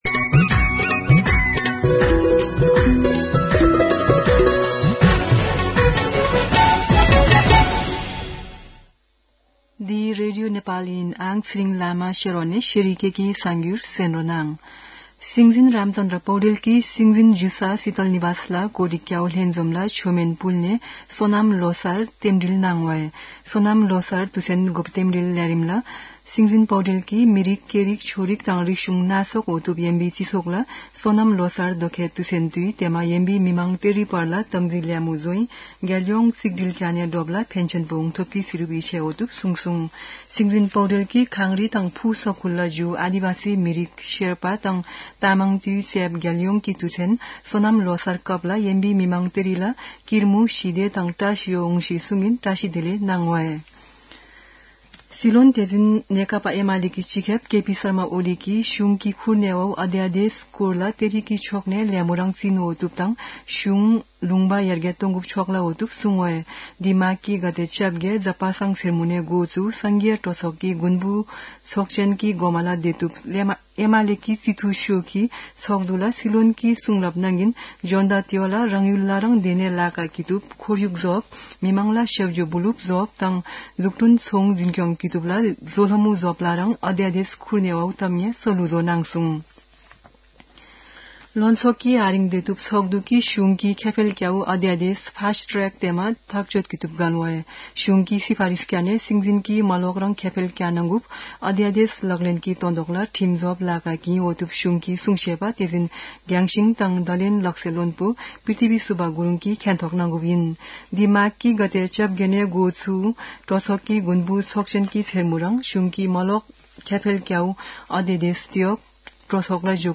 शेर्पा भाषाको समाचार : १६ माघ , २०८१
Sherpa-News-10.mp3